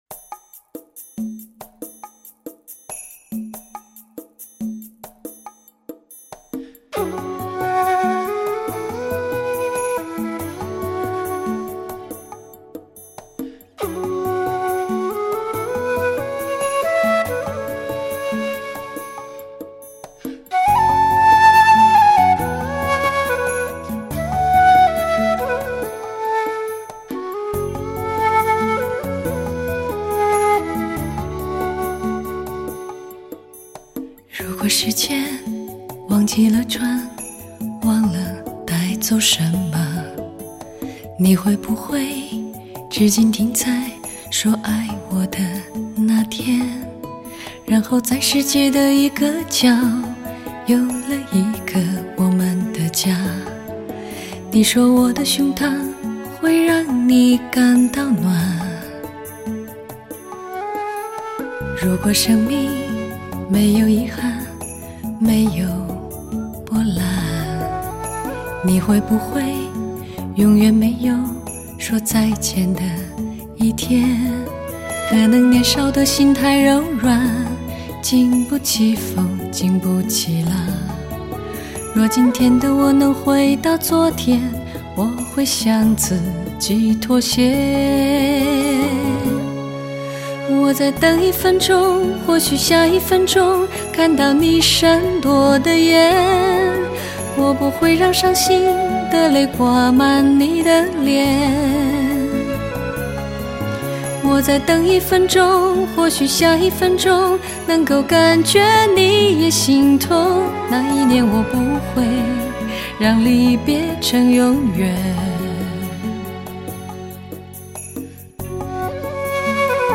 独特的声线 淡定从容的演唱风格 丝丝入扣的韵味 演绎最真的感动。
轻快晶莹的三角铁，招惹竹埙哭泣